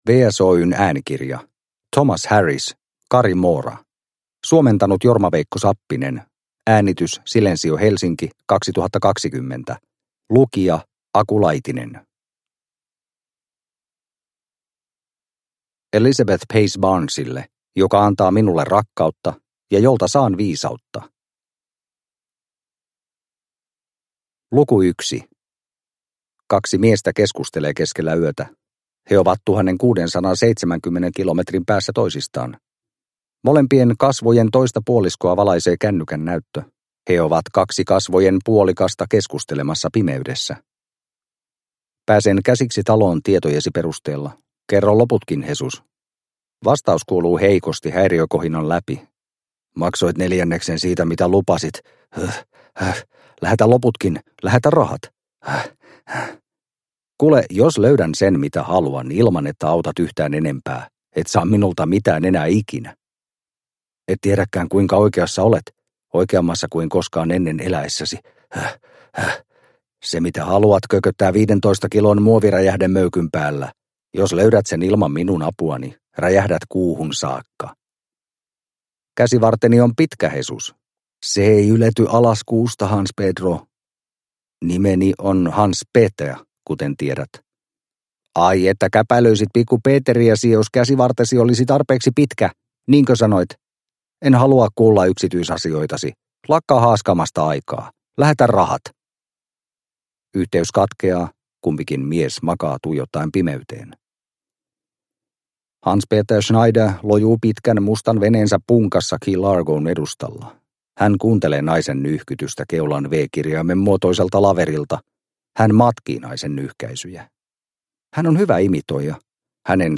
Cari Mora – Ljudbok – Laddas ner